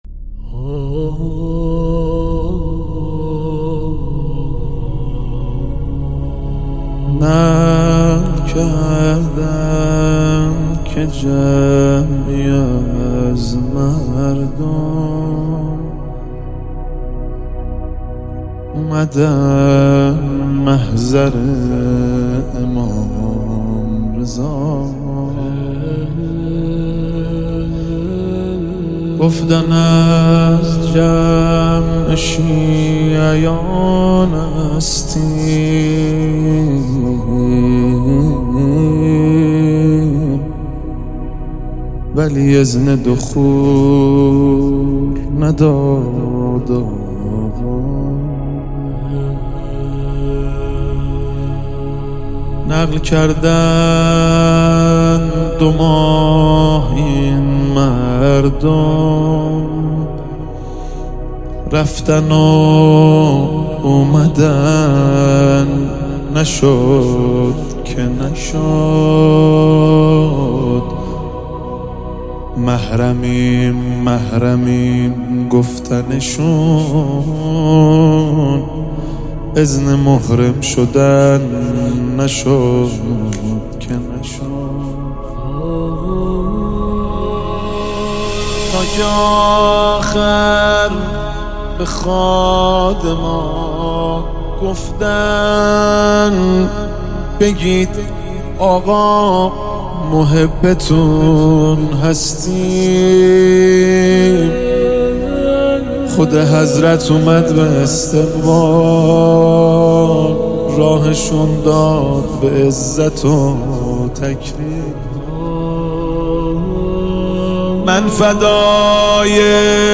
مناجات با امام رضا علیه السلام - مهدی رسولی با ترافیک رایگان